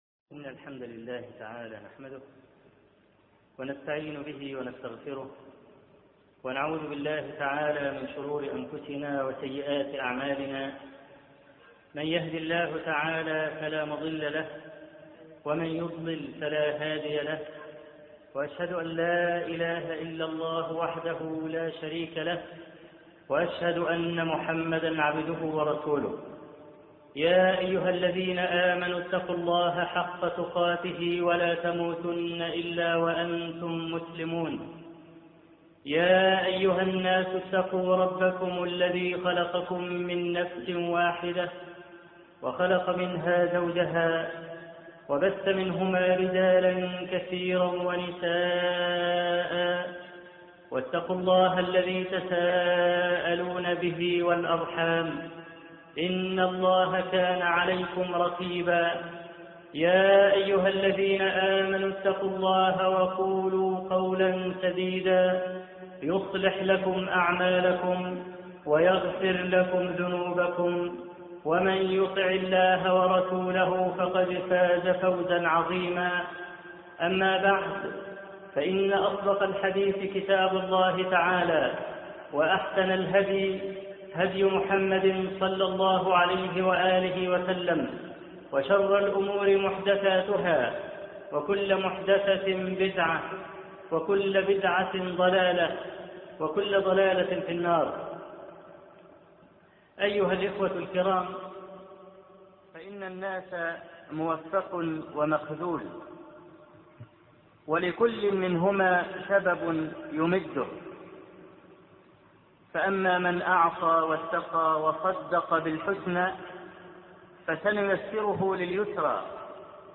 قصة أصحاب الكهف درس نادر - الشيخ أبو إسحاق الحويني